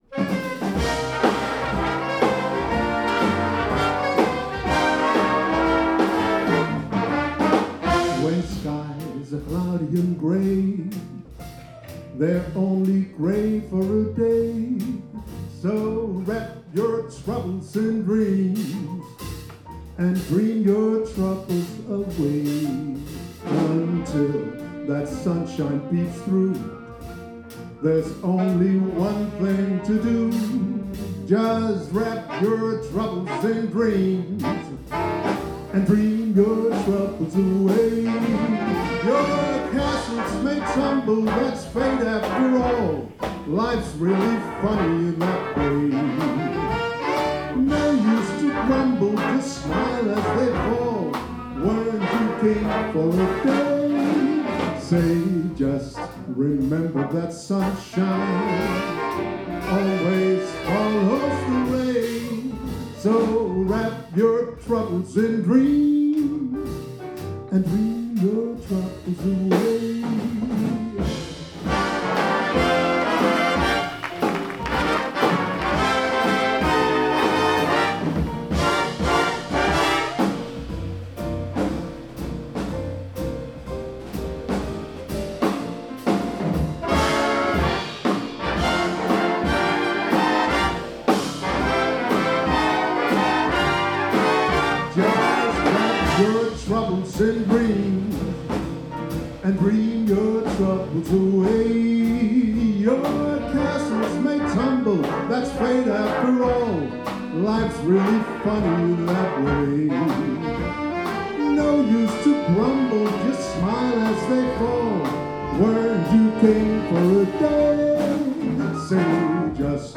Efterårs-Jule-Jazz-koncert
Udstyret er én digital stereo mikrofon, ikke en studieoptagelse !